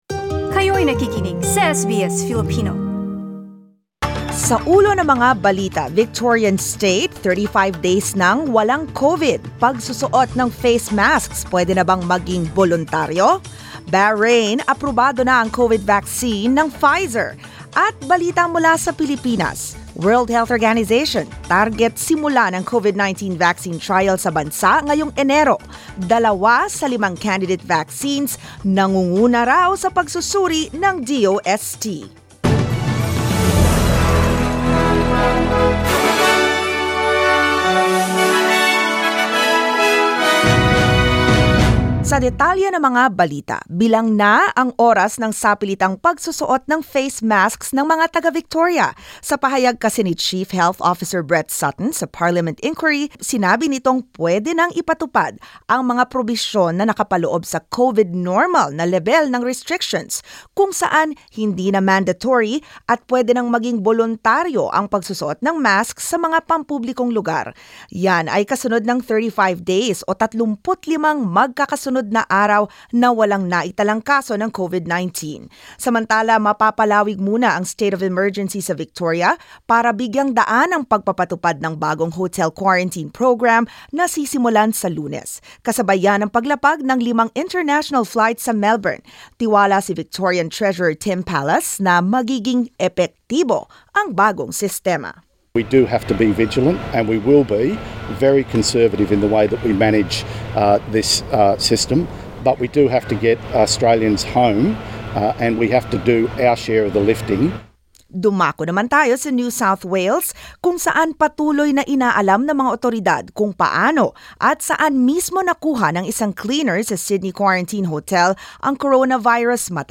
Mga balita ngayong ika-5 ng Disyembre